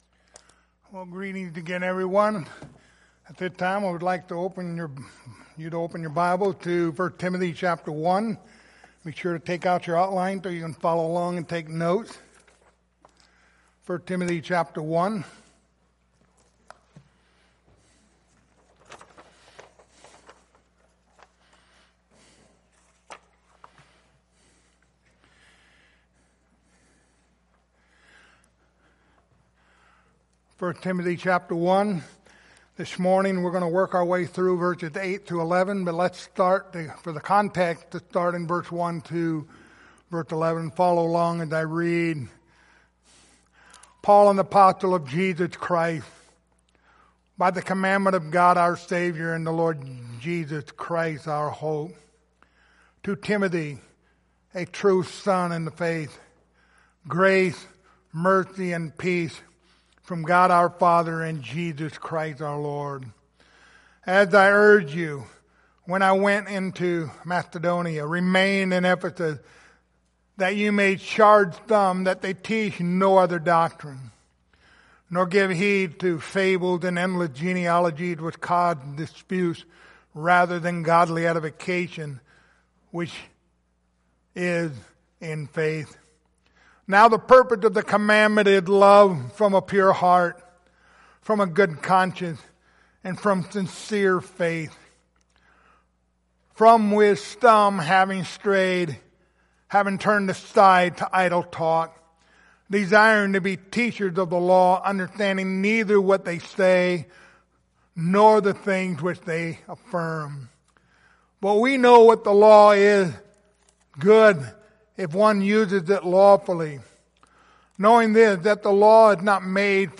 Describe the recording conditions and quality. Passage: 1 Timothy 1:8-11 Service Type: Sunday Morning